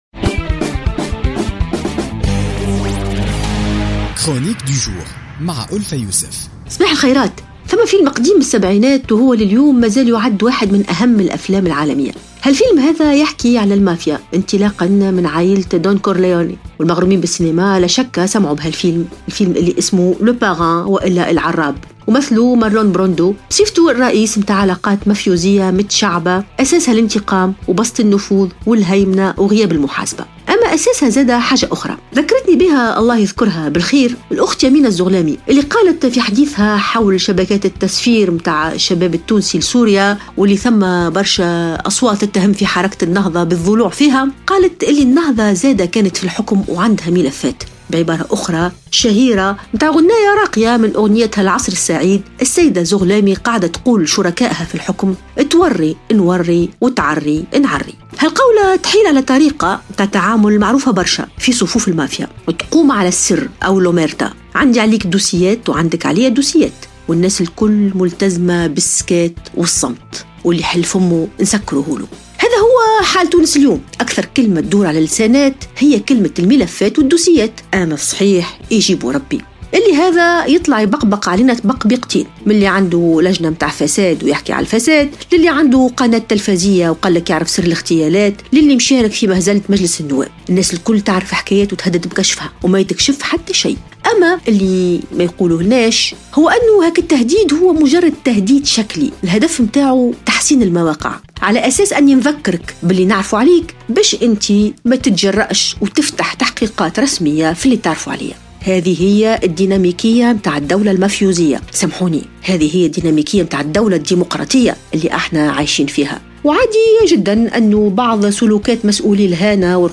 انطلقت ألفة يوسف في افتتاحيتها لليوم الجمعة 31 مارس 2017 من فيلم عالمي شهير وهو فيلم...